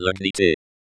[ləɡniːˈtɪ] lab for sustainable and resilient logistics in India, at IIT Madras.
ləɡniːˈtɪ.wav